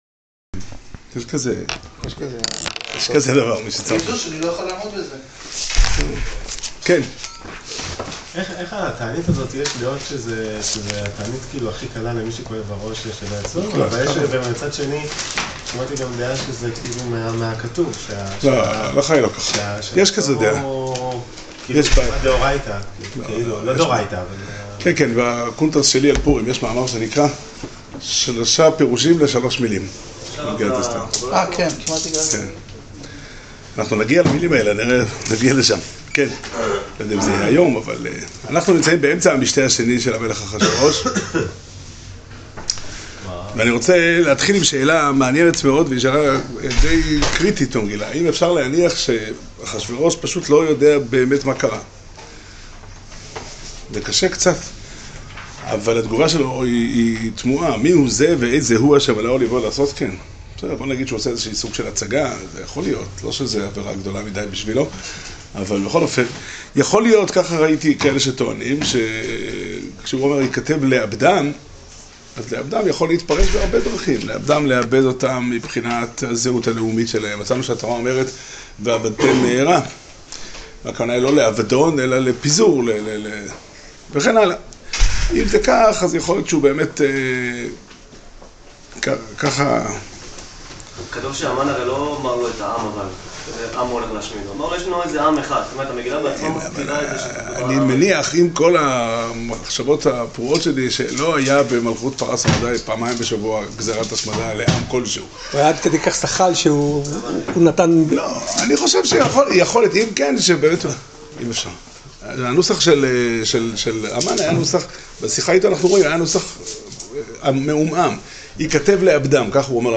שיעור שנמסר בבית המדרש פתחי עולם בתאריך י"ב אדר ב' תשע"ט